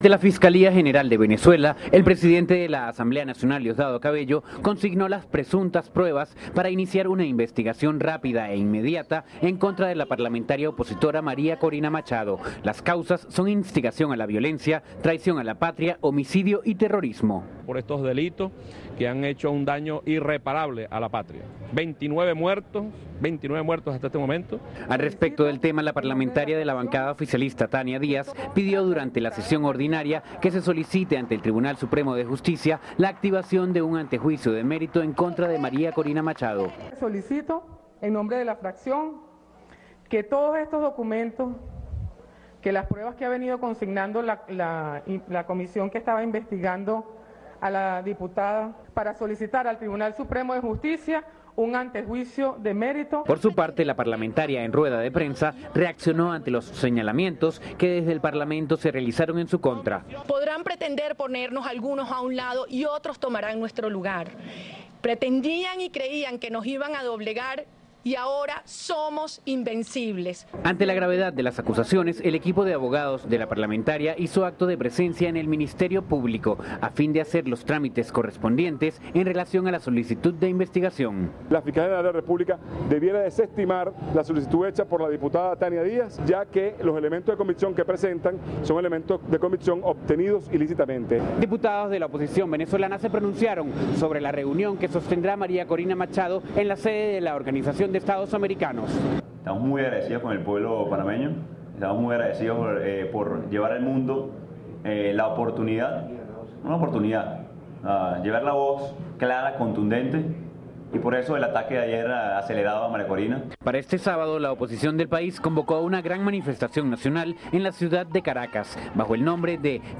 En Venezuela, el bloque oficialista de la Asamblea Nacional solicitará que se inicien las acciones que lleven a un antejuicio de perito contra la diputada María Corina Machado. Informa desde Caracas